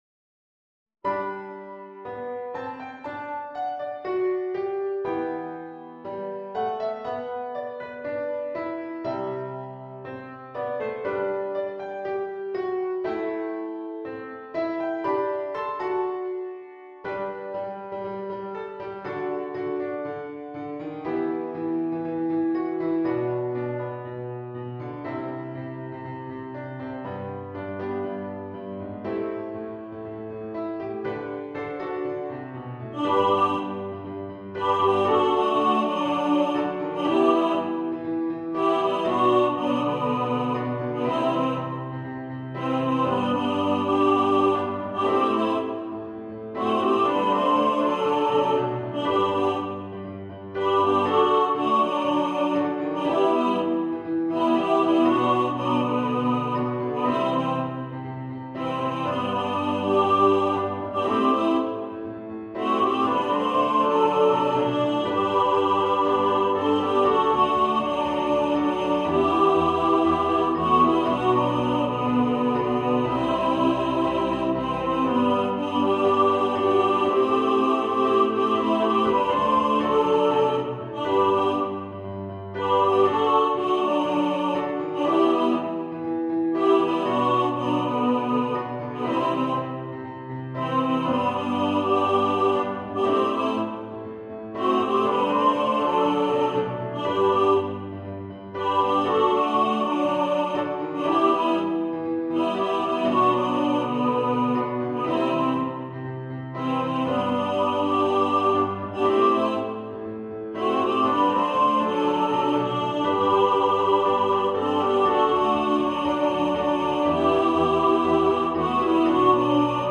Hier erhalten Sie die dreistimmige Chorversion!